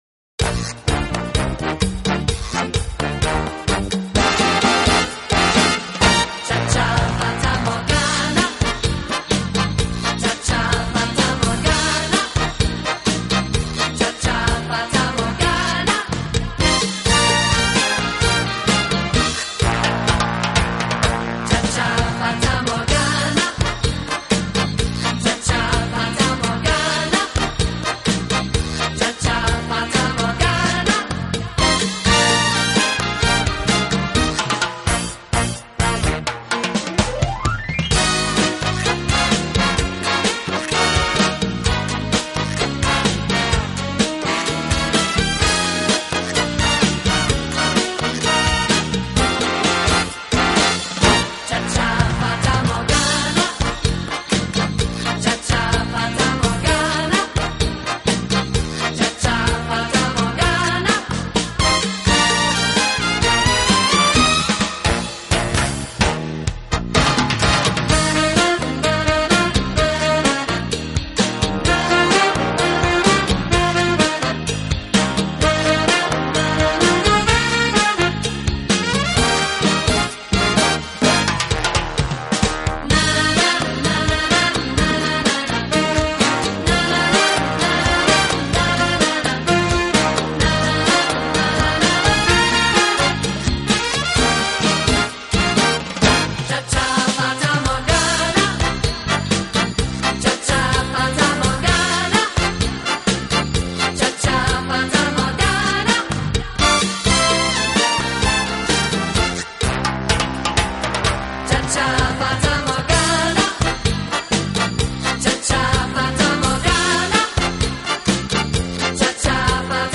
【轻音乐】
有动感，更有层次感；既有激情，更有浪漫。
Cha cha cha